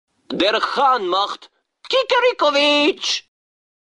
Der Hahn macht.mp3